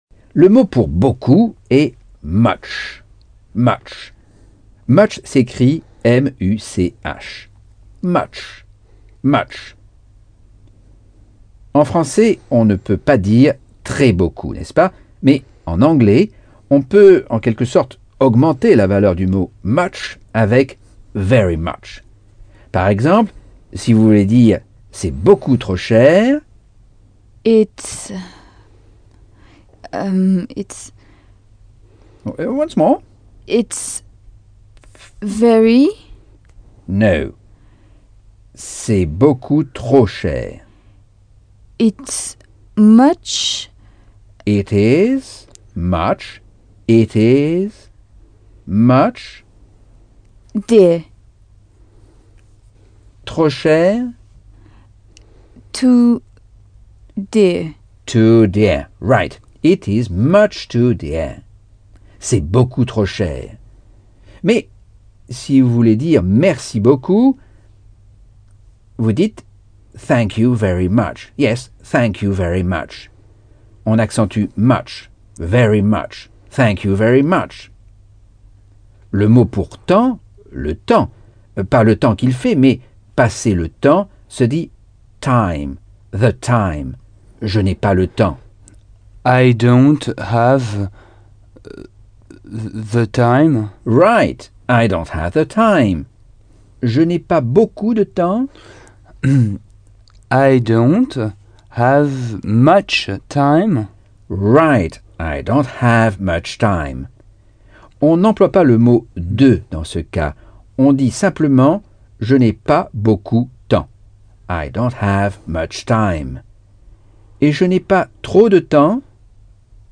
Leçon 5 - Cours audio Anglais par Michel Thomas